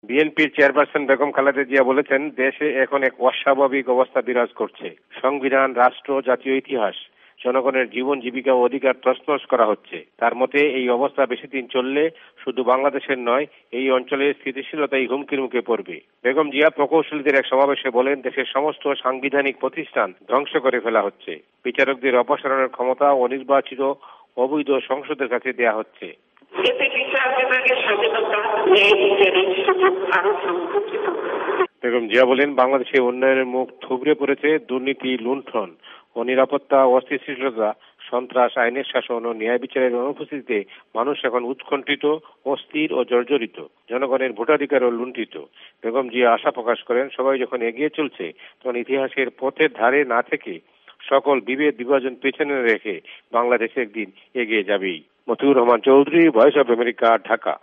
ভয়েস অফ এ্যামেরিকার বাংলাদেশ সংবাদদাতাদের রিপোর্ট